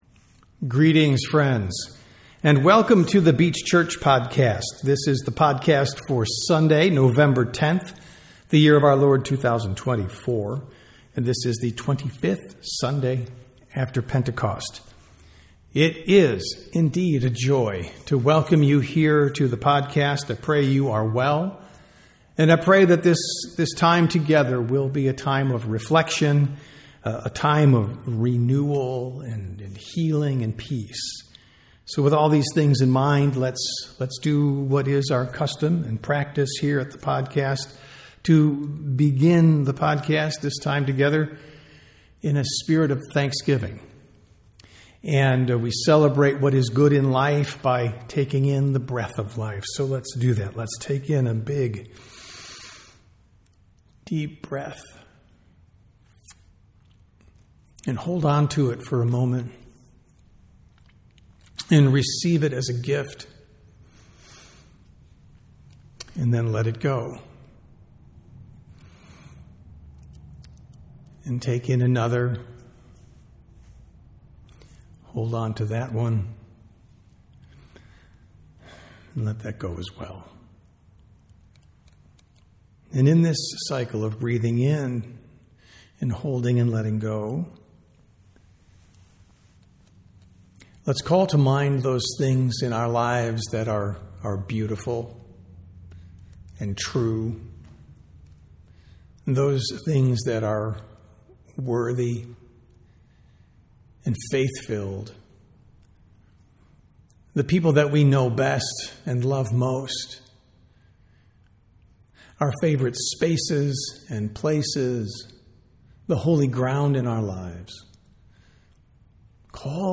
Sermons | The Beach Church
Sunday Worship - November 10, 2024